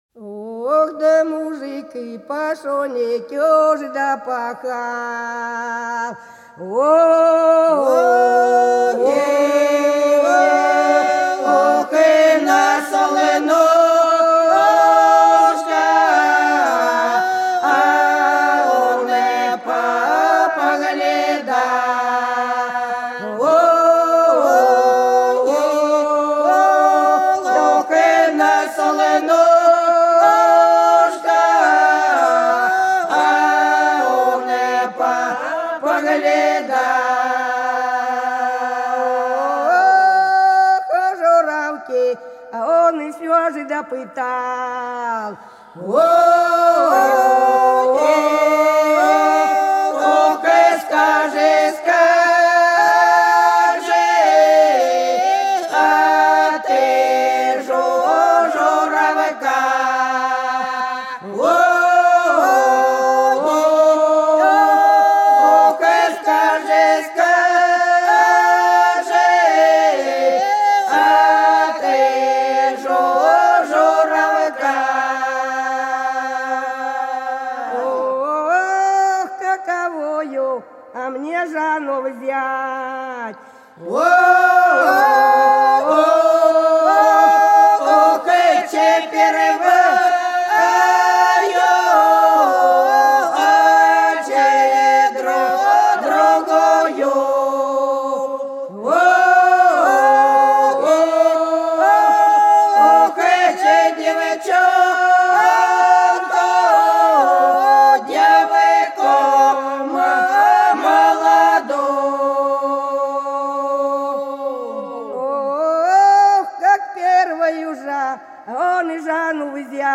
Хороша наша деревня Мужик пашенку пахал - протяжная (с. Иловка)
05_Мужик_пашенку_пахал_-_протяжная.mp3